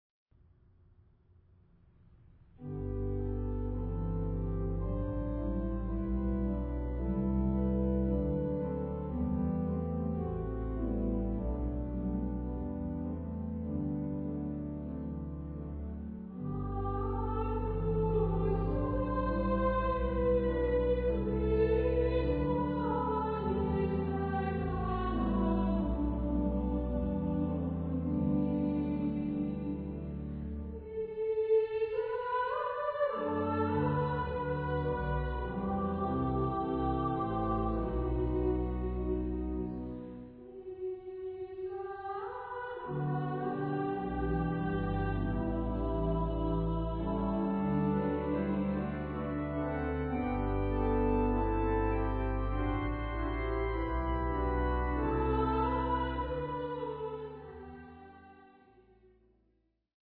Classical
Choral